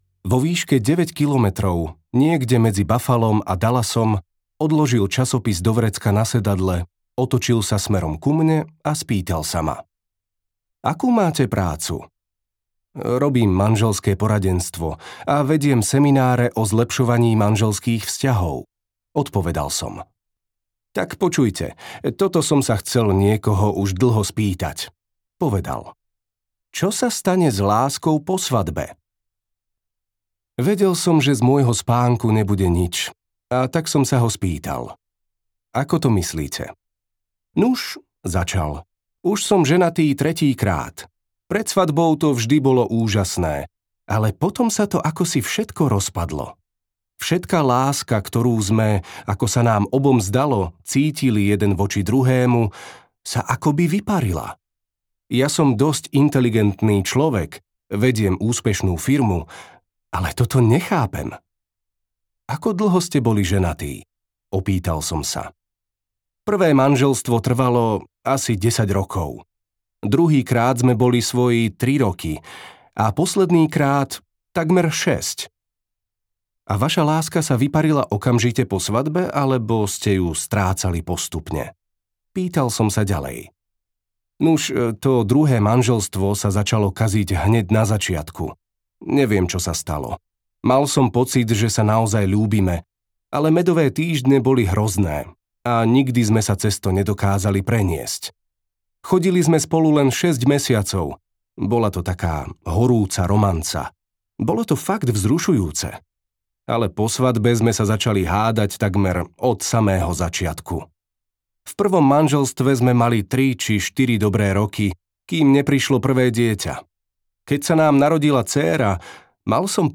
5 jazykov lásky audiokniha
Ukázka z knihy